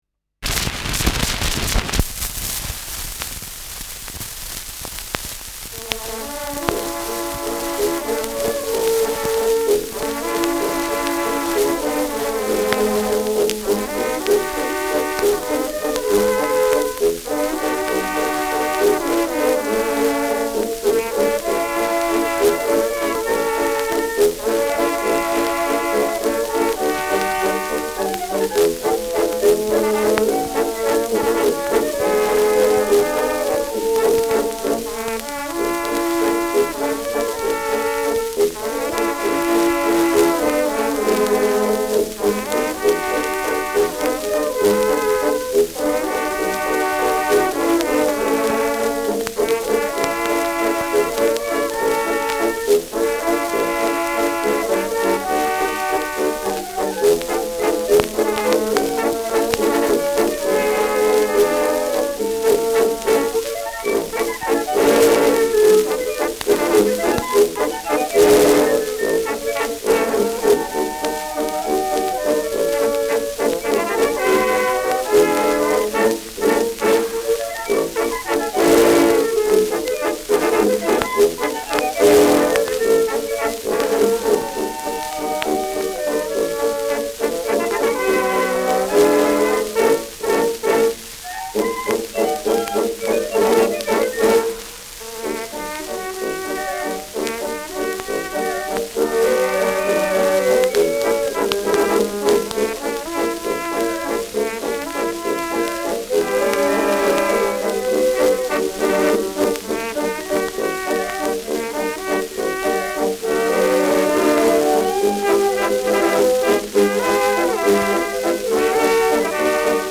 Freundschaftswalzer : Sächsisch-Thüringischer Kirmestanz
Schellackplatte
Starkes Rauschen : Gelegentlich stärkeres Knacken
[unbekanntes Ensemble] (Interpretation)